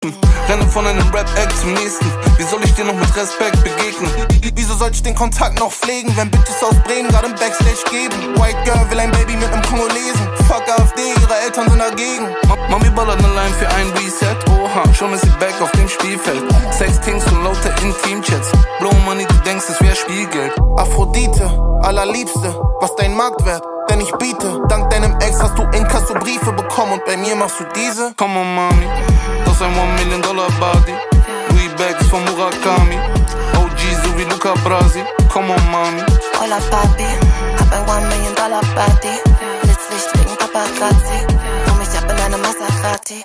Kategorien Rap